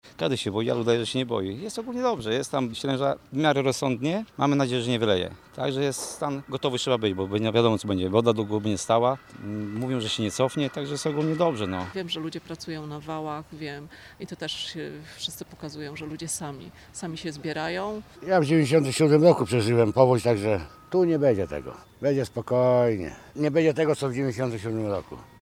sonda-przedmiescie.mp3